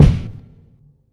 Kicks
reveb_kick.wav